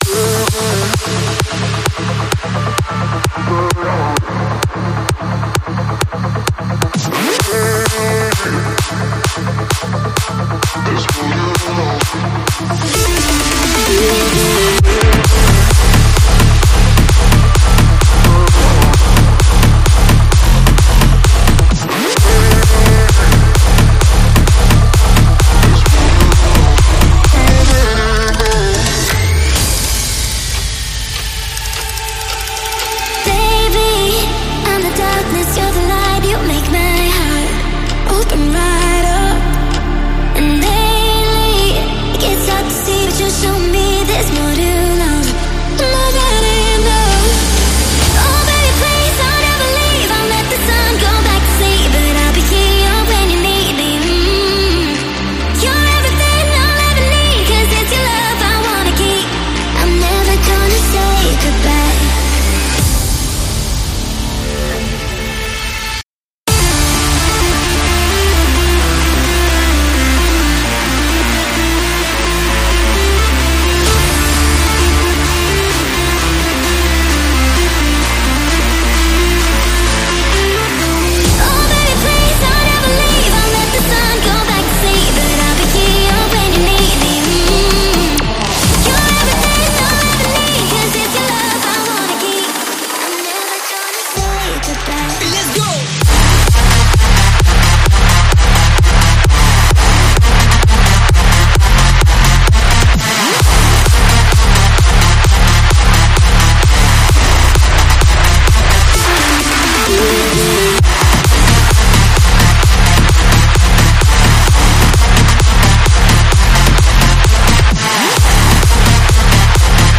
试听文件为低音质，下载后为无水印高音质文件 M币 8 超级会员 免费 购买下载 您当前未登录！